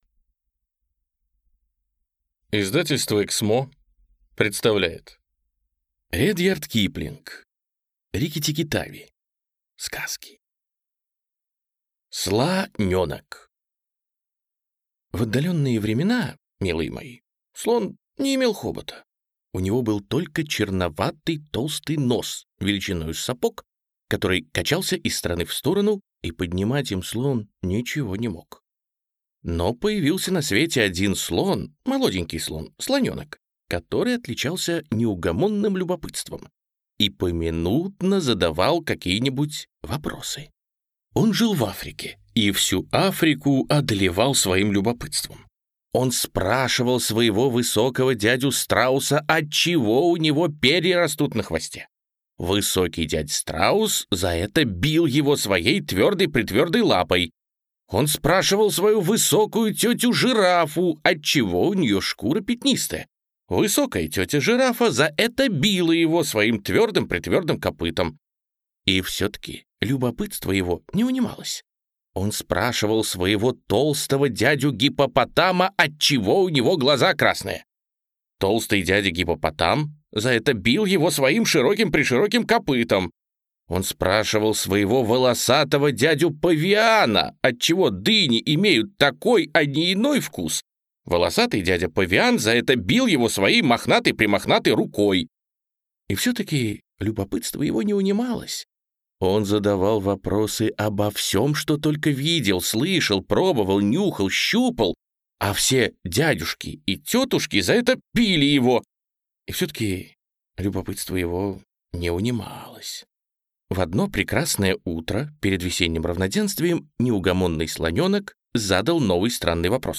Аудиокнига Рикки-Тикки-Тави.